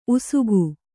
♪ usugu